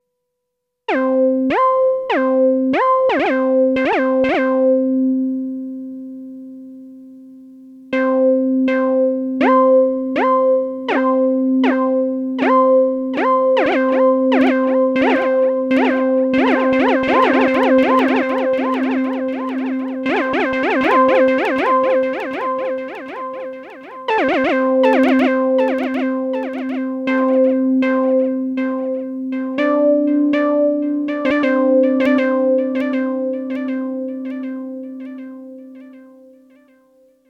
SY RAW machine
The clicks occurred on fast melodies.
Clicks start at 0:14. There are clean sound, and delay on slow notes at the beginning of the sample to show there are no clicks.